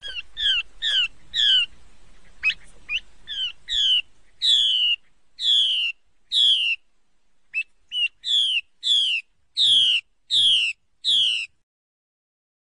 高质量画眉母鸟叫声